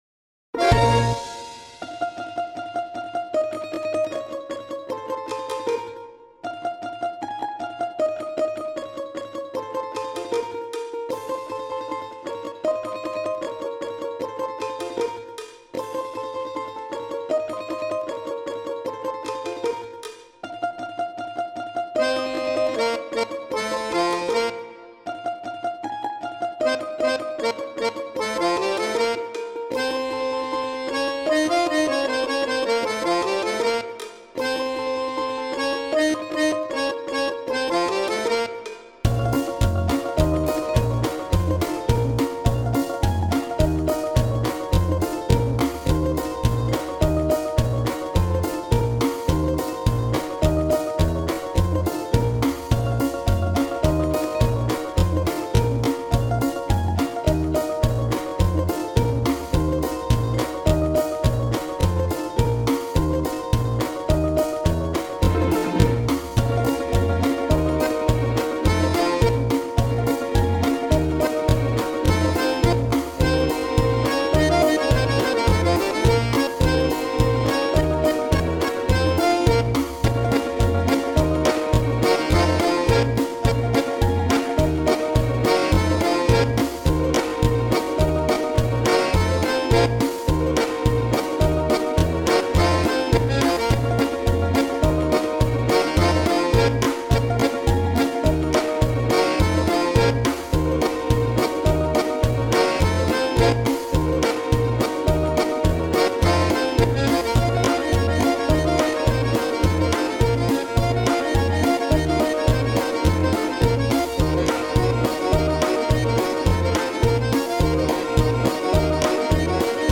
ugadaj_melodiju___vo_pole_bereza_stojala_minus_z2_fm.mp3